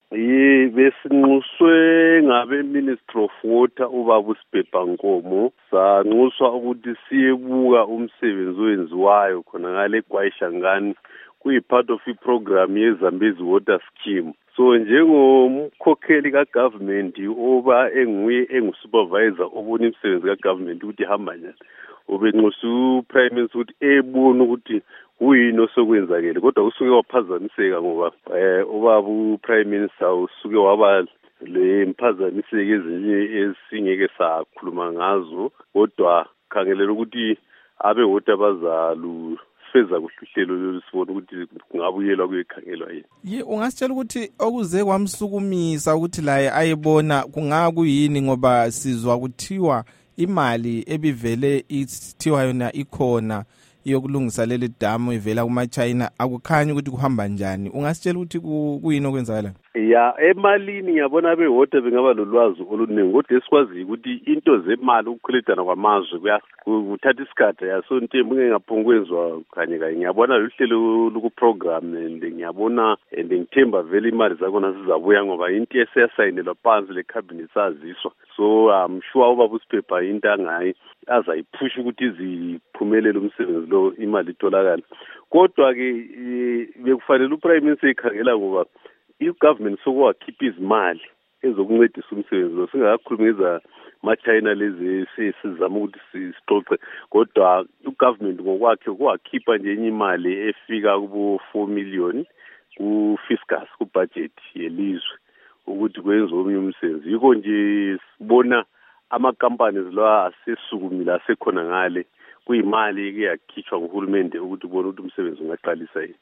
Ingxoxo loMnu.